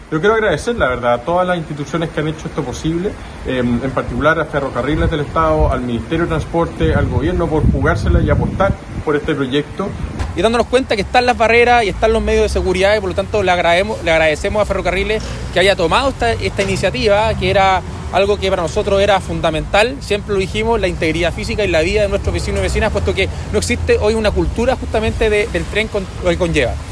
El alcalde de Puerto Varas, Tomás Gárate, agradeció a todas las instituciones que hicieron posible el retorno del tren; mientras que su par de Puerto Montt, Rodrigo Wainraihgt destacó que se implementaran medidas de seguridad como las barreras.
alcaldes-puerto-montt-llanquihue.mp3